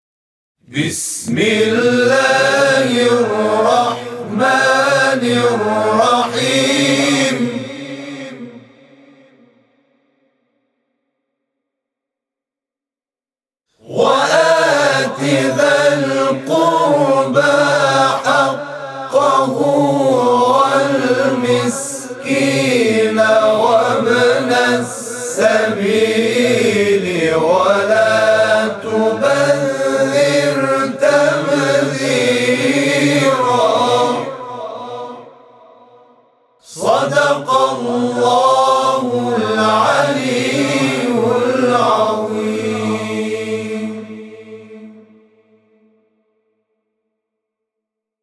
صوت همخوانی آیه 26 سوره اسراء از سوی گروه تواشیح «محمد رسول‌الله(ص)»